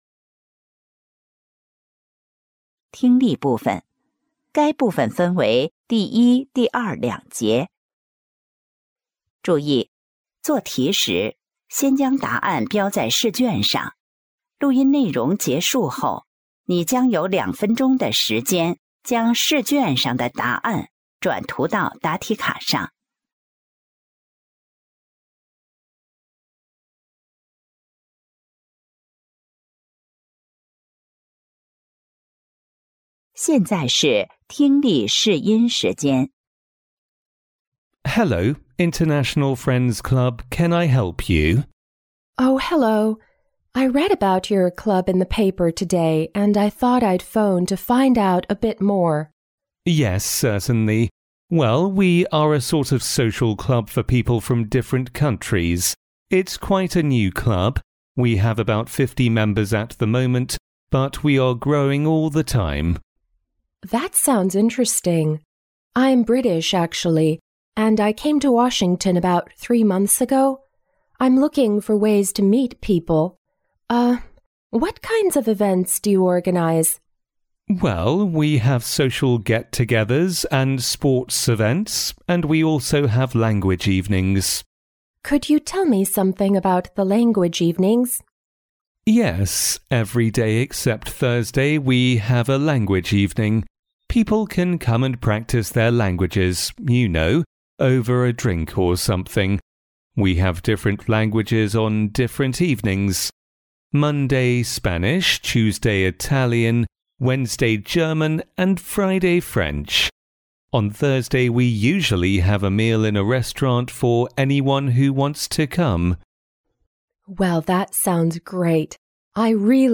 四川金太阳2026届高三上学期10月联考英语听力.mp3